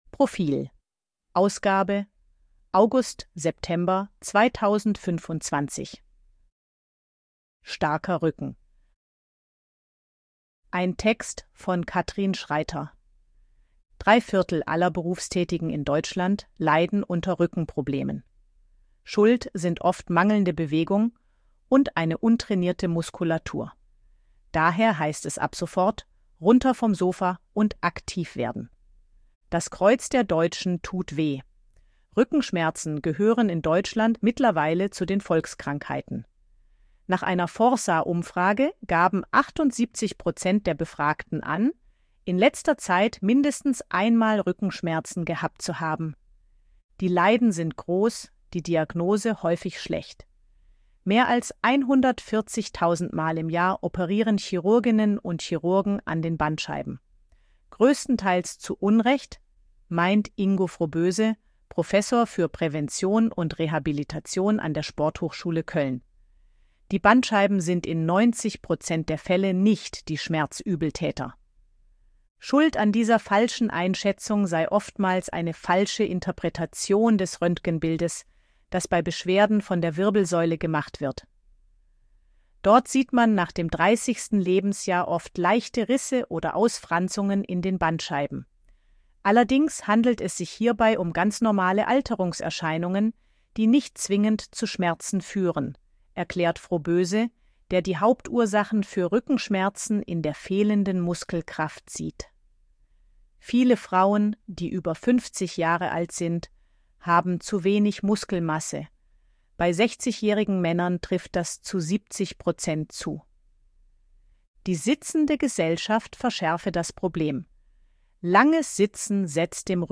ElevenLabs_252_KI_Stimme_Frau_Service_Leben_v2.ogg